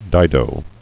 (dīdō)